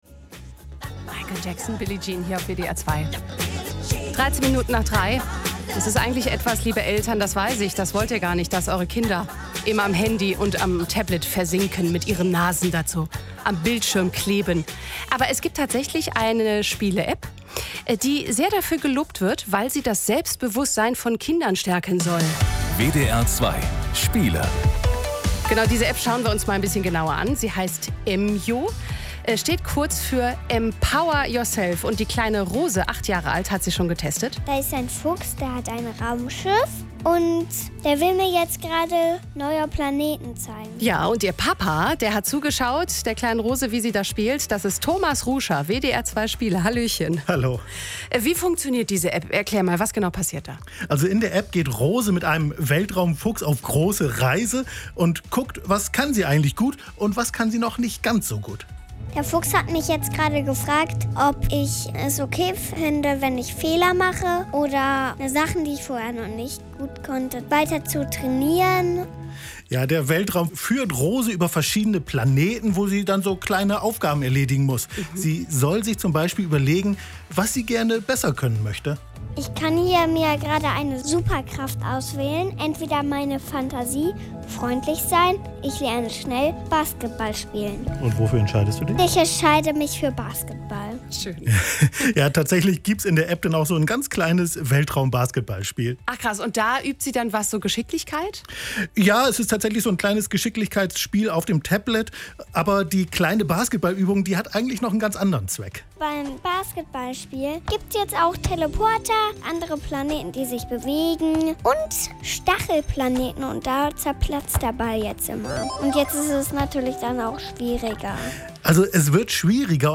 Radio-Bericht über EMYO (WDR2, 6.1.2025)